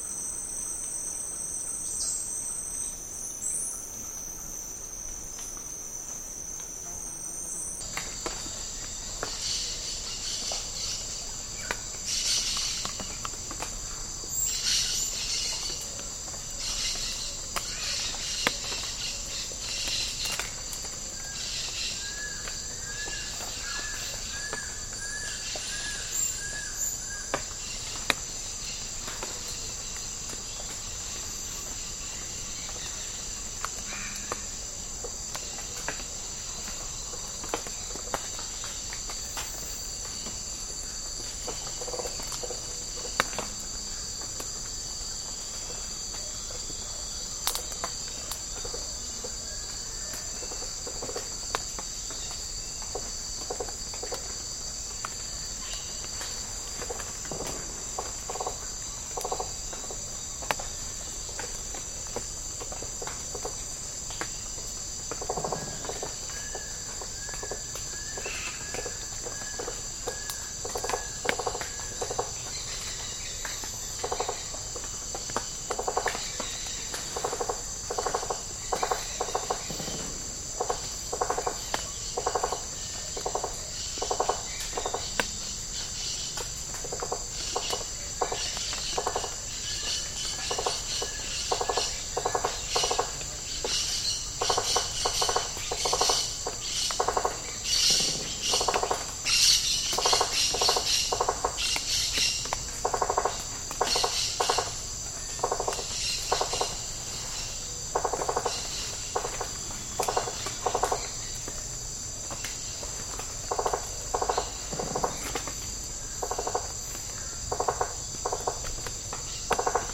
• tropical rainforest habitat insects.wav
tropical_rainforest_habitat_insects_TpN.wav